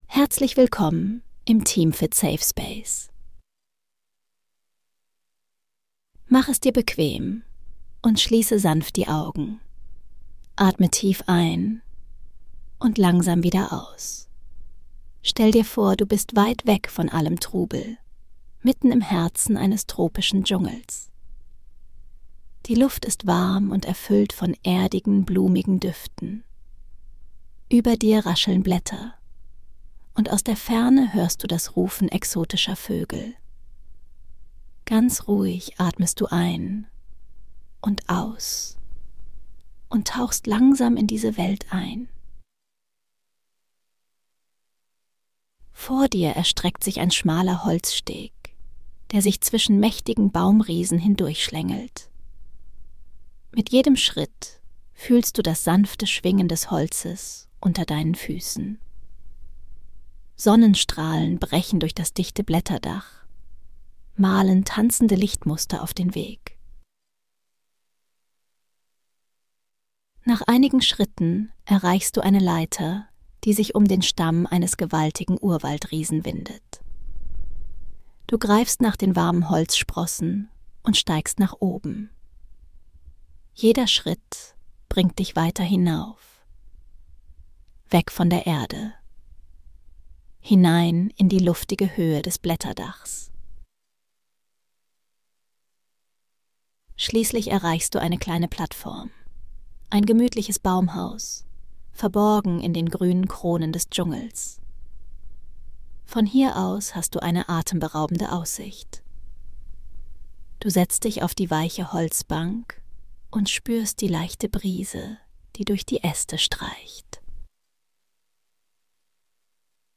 Eine meditative Reise in die luftigen Höhen des Regenwaldes